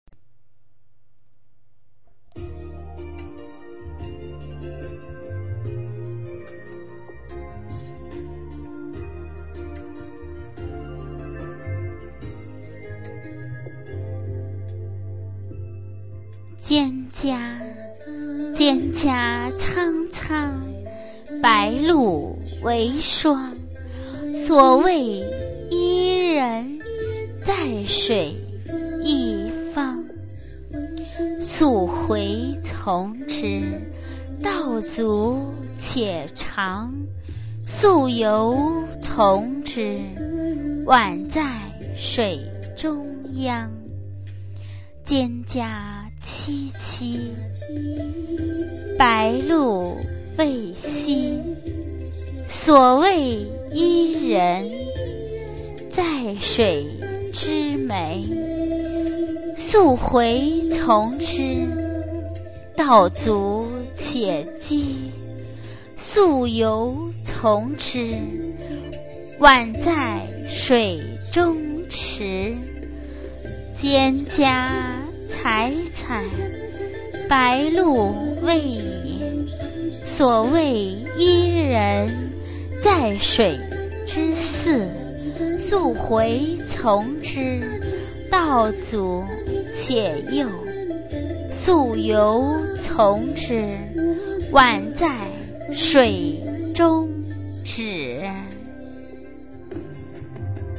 《蒹葭》原文与译文（含赏析及朗读）